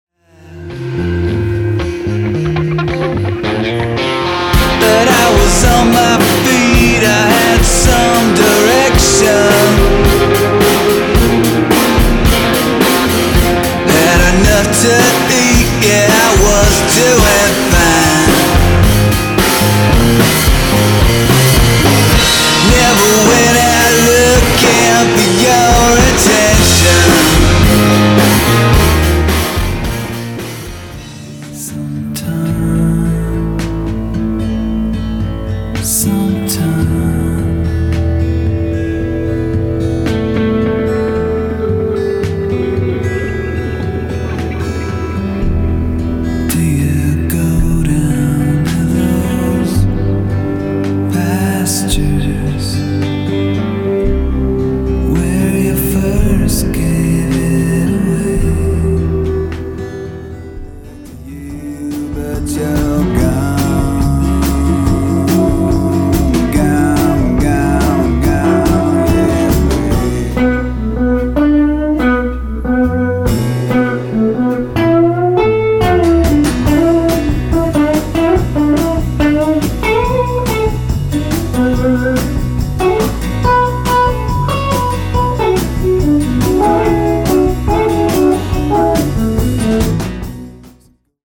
Drums and percussion
in the same room of a small garage studio in East Nashville